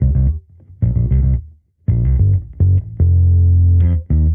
Index of /musicradar/sampled-funk-soul-samples/110bpm/Bass
SSF_PBassProc1_110C.wav